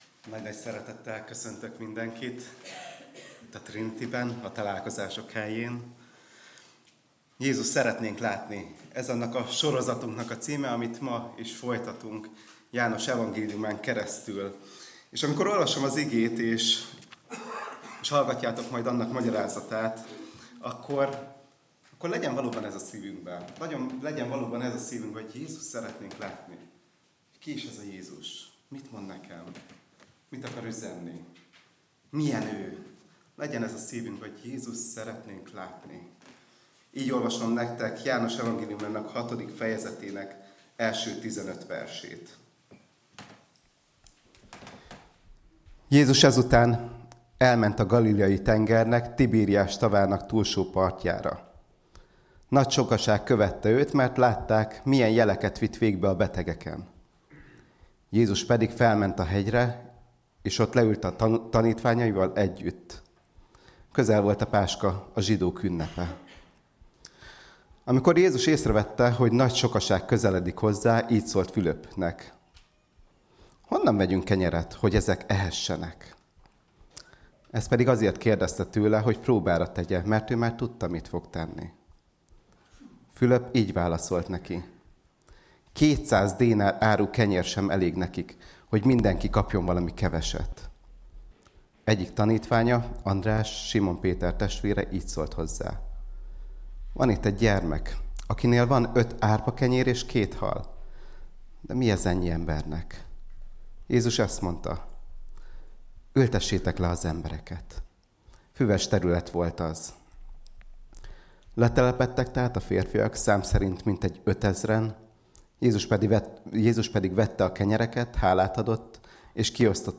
Igehirdetés